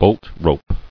[bolt·rope]